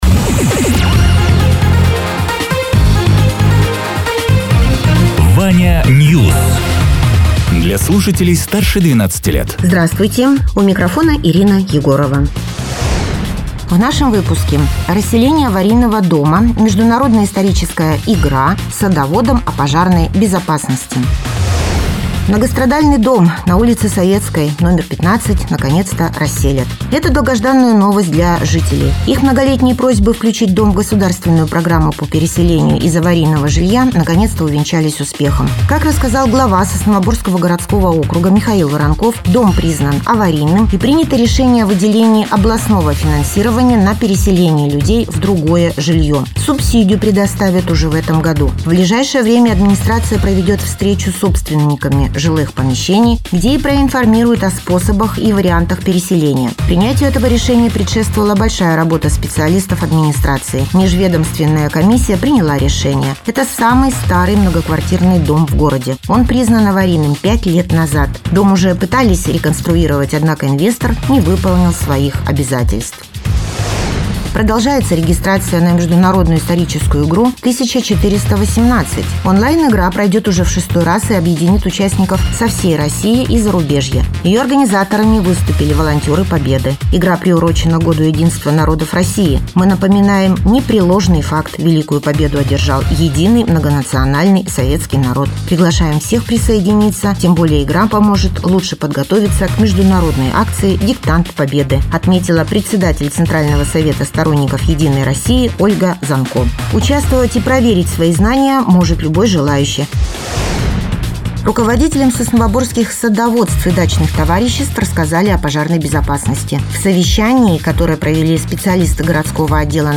Радио ТЕРА 15.03.2026_10.00_Новости_Соснового_Бора